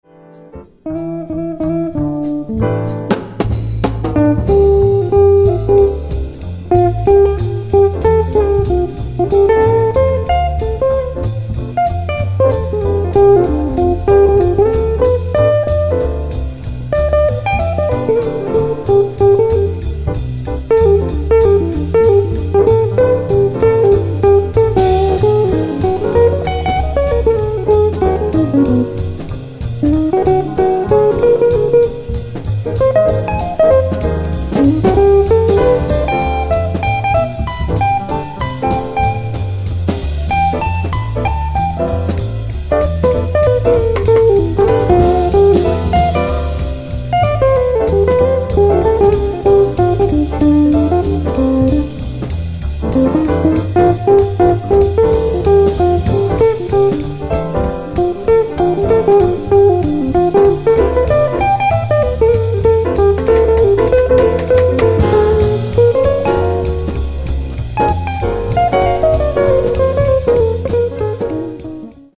- pentatonica minore di D e di E sul primo accordo
- pentatonica minore di Eb e di F sul secondo accordo